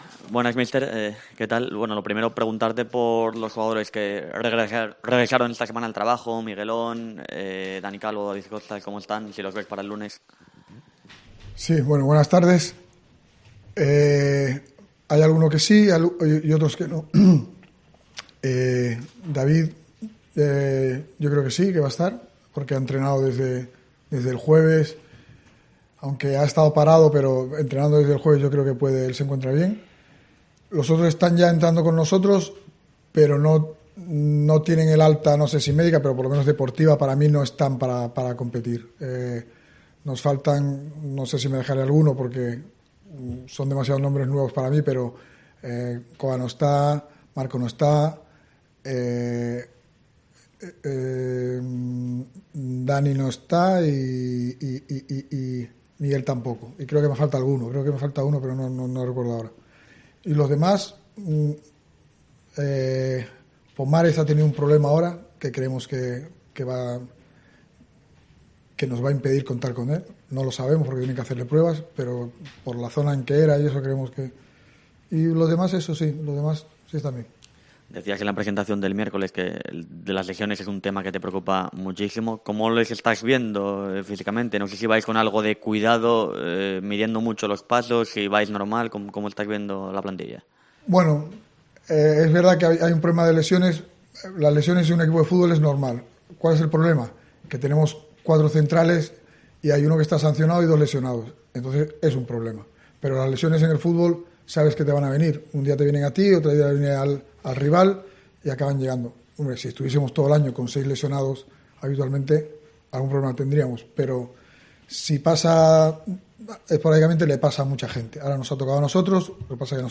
Rueda de prensa Álvaro Cervera (previa Málaga)